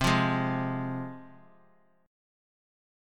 Csus4#5 chord